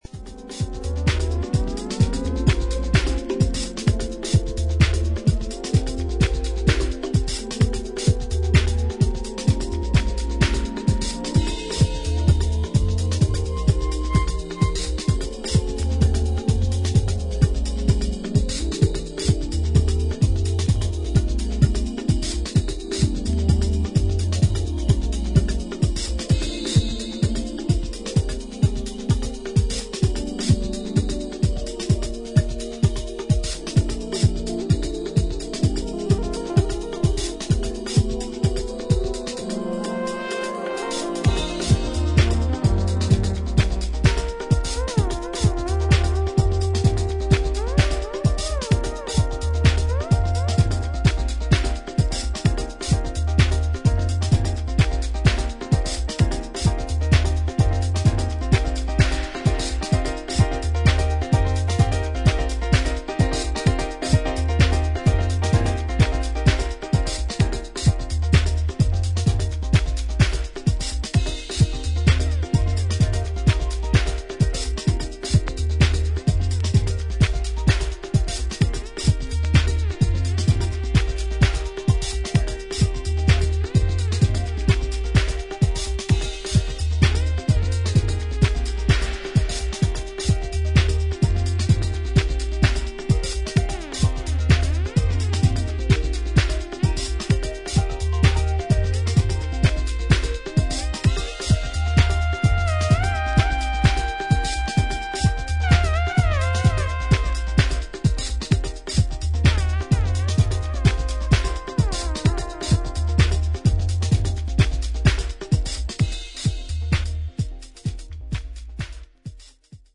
ディスコサンプルやダブ、ジャズのエレメンツを良い塩梅に取り入れたアンダーグラウンド・ディープハウス名盤。